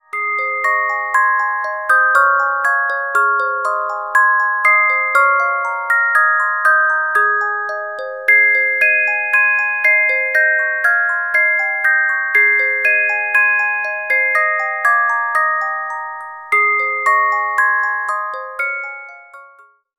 Music Box Melodies柔美音樂盒